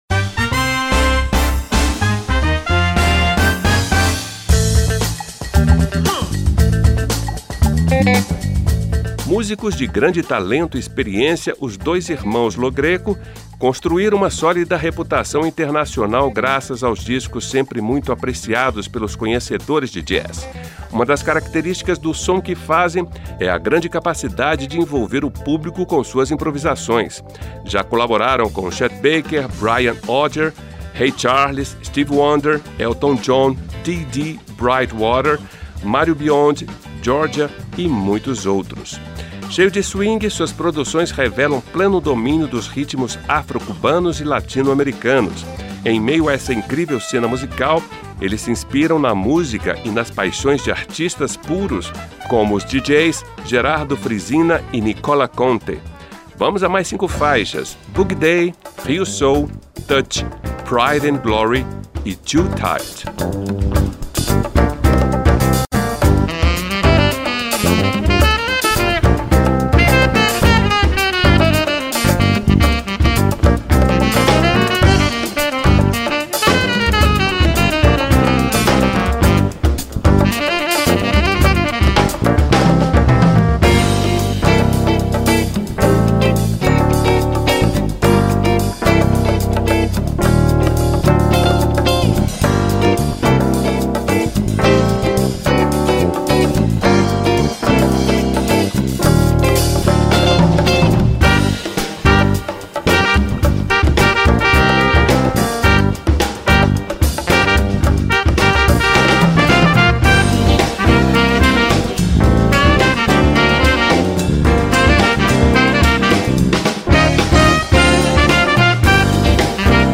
um coletivo de músicos
com uma proposta que une o jazz ao funk e ao soul.
no baixo
na bateria e percussão
piano
sax